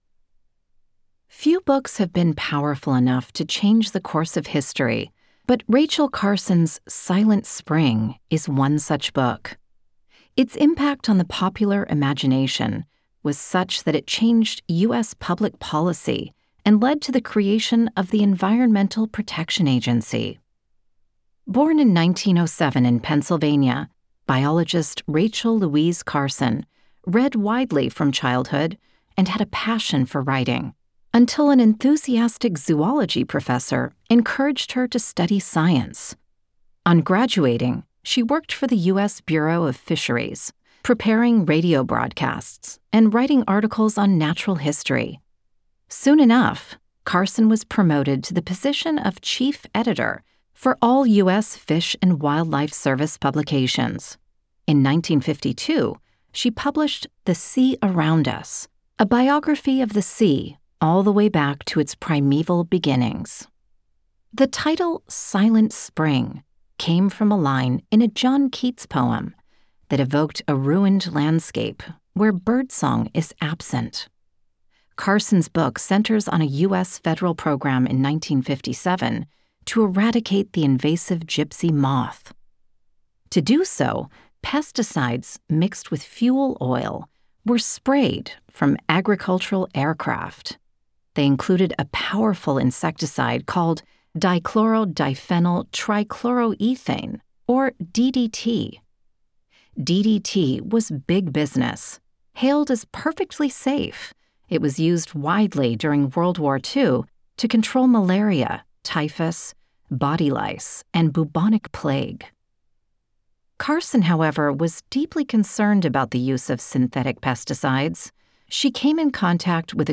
Speaker (American accent)
Speaker (UK accent)